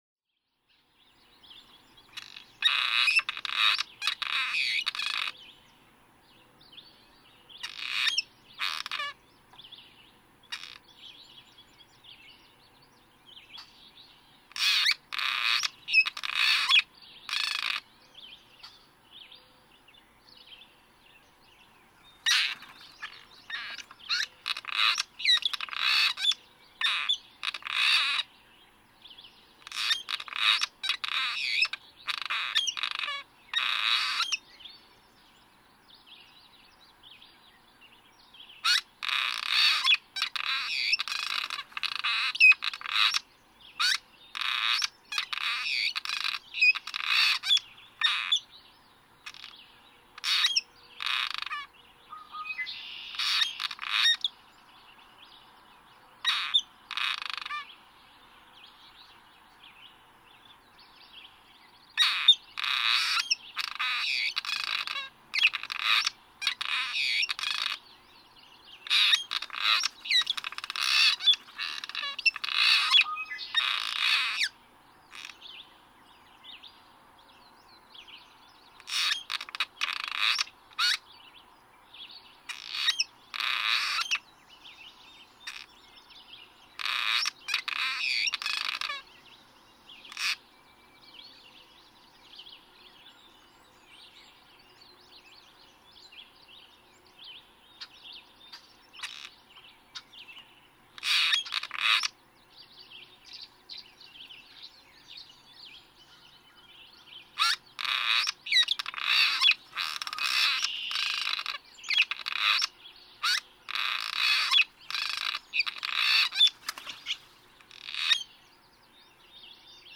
Cliff swallow
Get up close to a singing cliff swallow and just listen to all he does!!
Graves Farm, Williamsburg, Massachusetts.
677_Cliff_Swallow.mp3